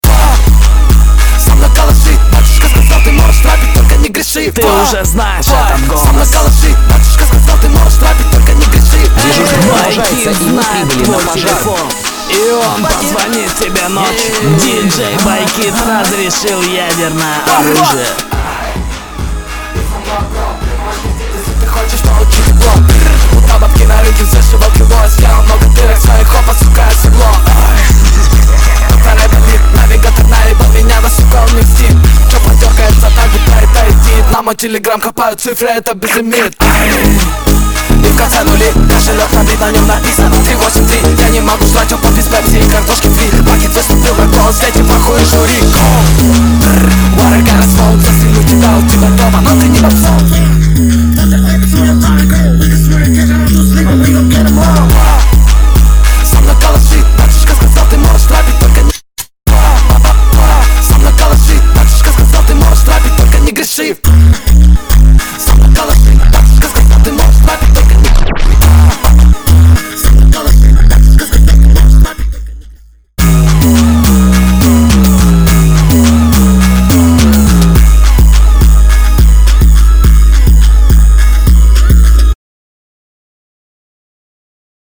Рэп и Хип-Хоп